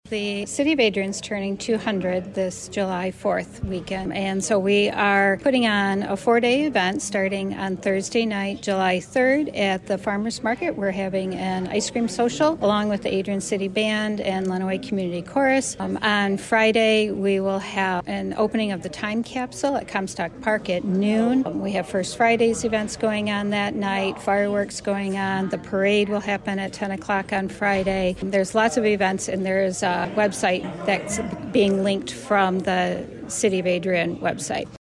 WLEN News was able to speak with Commissioner Roberts about the once-in-a-lifetime celebration, which is expected to feature new events alongside annual traditions…
mary-roberts-comment-1.mp3